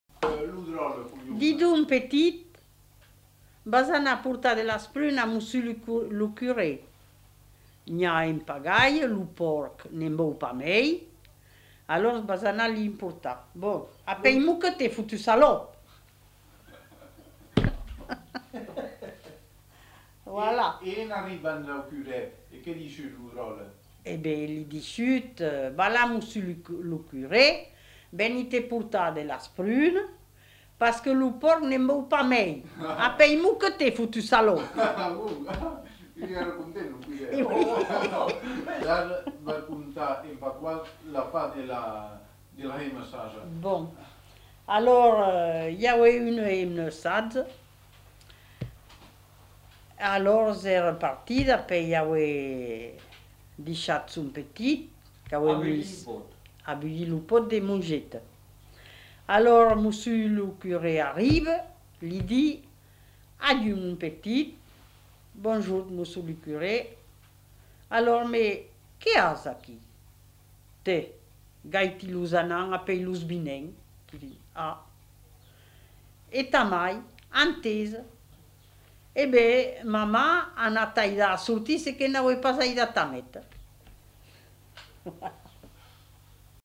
Aire culturelle : Médoc
Genre : conte-légende-récit
Effectif : 1
Type de voix : voix de femme
Production du son : parlé